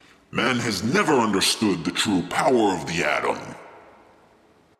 描述：科幻相关的文字由男性说。用AT2020 + USB录制。有效果。